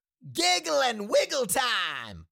Cartoon Little Monster, Voice, Giggle And Wiggle Time Sound Effect Download | Gfx Sounds
Cartoon-little-monster-voice-giggle-and-wiggle-time.mp3